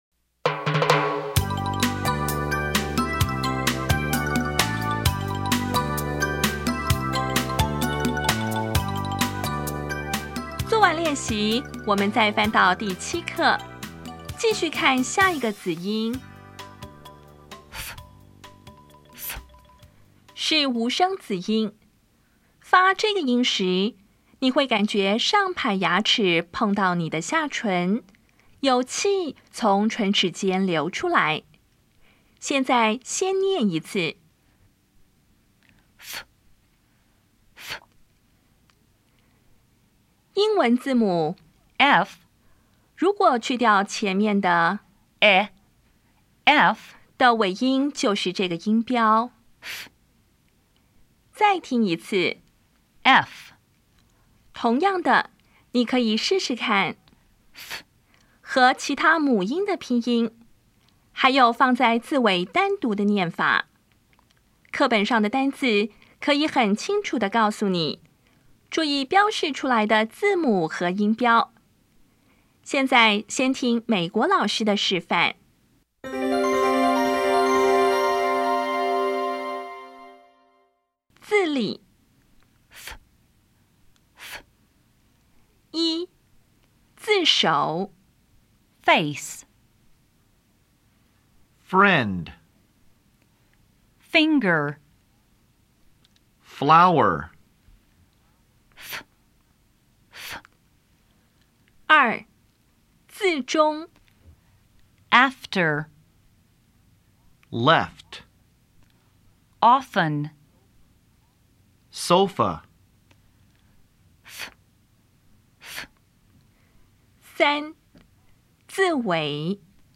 当前位置：Home 英语教材 KK 音标发音 子音部分-1: 无声子音 [f]
音标讲解第七课
[fes]
[frɛnd]